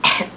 Cough (female) Sound Effect
Download a high-quality cough (female) sound effect.
cough-female.mp3